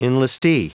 Prononciation audio / Fichier audio de ENLISTEE en anglais
Prononciation du mot : enlistee